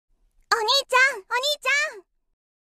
Notifikasi WA Anime Onii chan
Kategori: Nada dering
nada-notifikasi-wa-anime-onii-chan-id-www_tiengdong_com.mp3